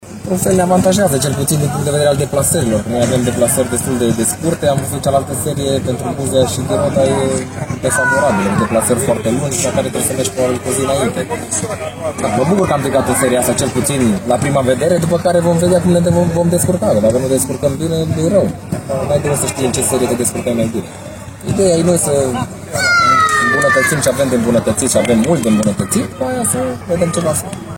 Tot ieri, Politehnica și-a aflat seria din care va face parte în viitoarea ediție a Ligii a III-a. Alb-violeții au fost repartizați alături de alte două grupări din Timiș, plus reprezentante din Arad și Bihor. Din acest punct de vedere, repartizarea e mulțumitoare, spune Paul Codrea: